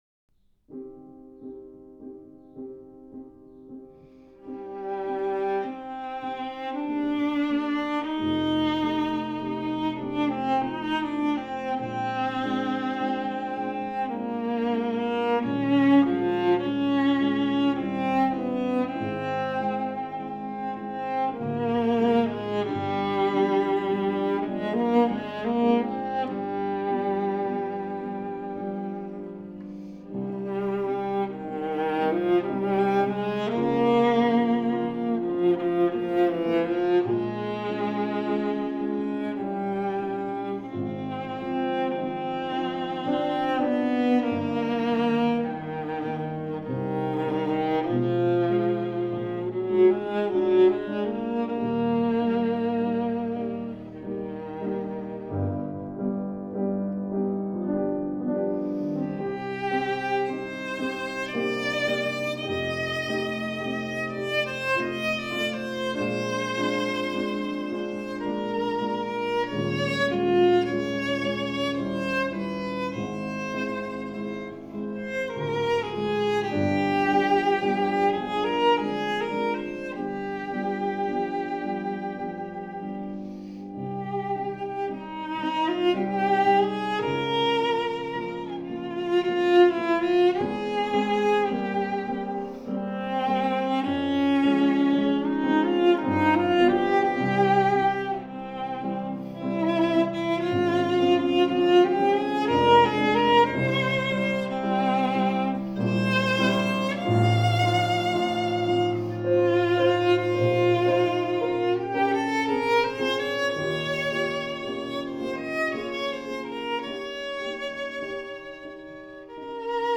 Klangvergleich
Viola B:
(Klavier)
Viola B: Jean Baptiste Vuillaume Anno 1858